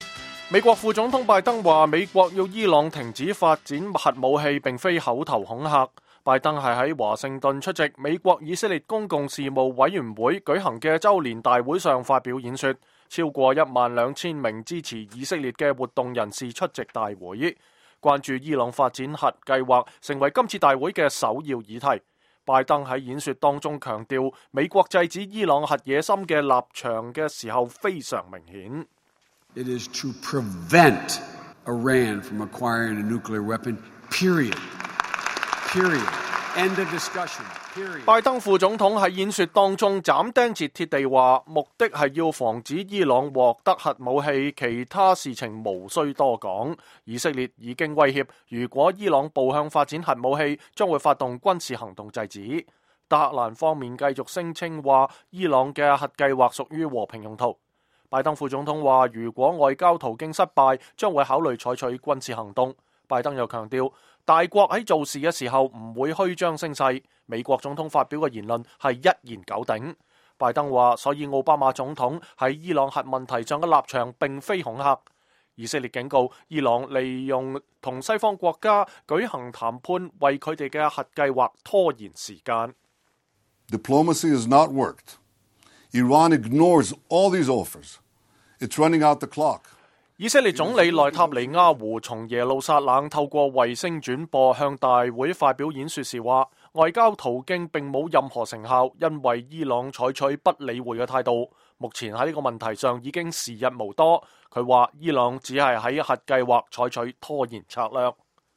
美國副總統拜登說﹐美國要伊朗停止發展核武器並非“口頭恐嚇”。拜登是在華盛頓出席美國-以色列公共事務委員會(AIPAC)舉行的週年大會上發表演說﹐超過一萬兩千名支持以色列的活動人士出席大會。